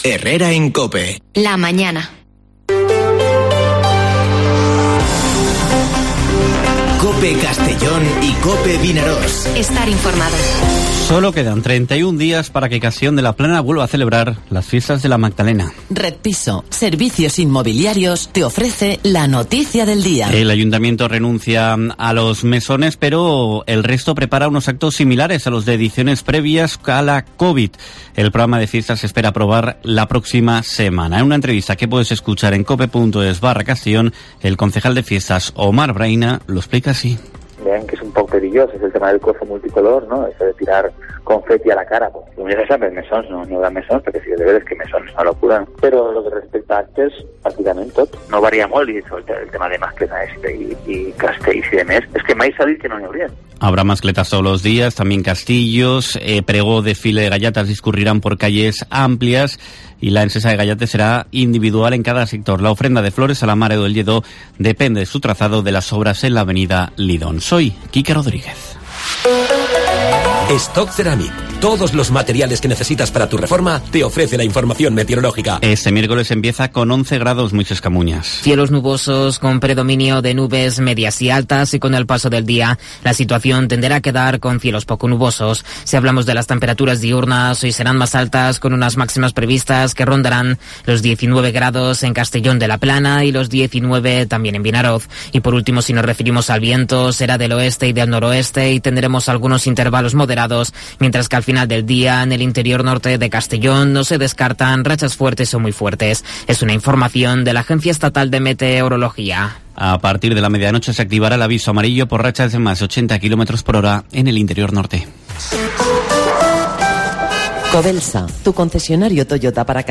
Informativo Herrera en COPE en la provincia de Castellón (16/02/2022)